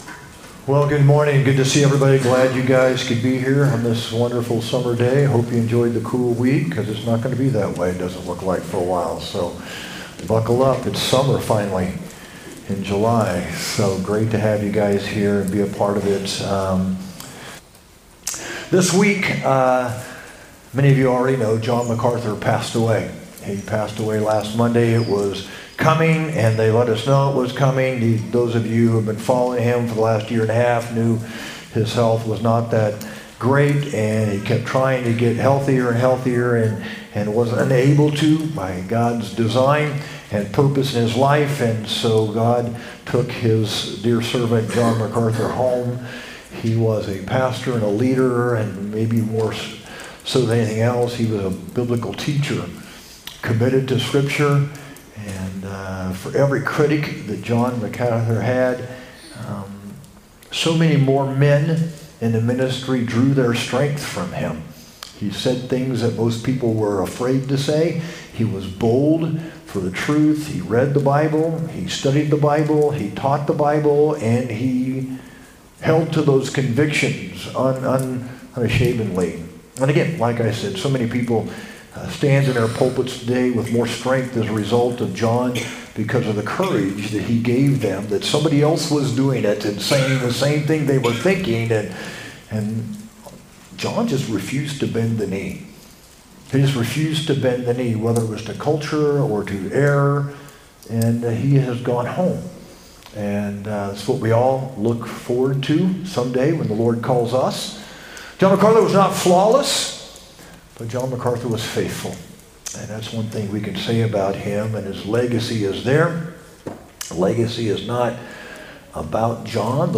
sermon-7-20-25.mp3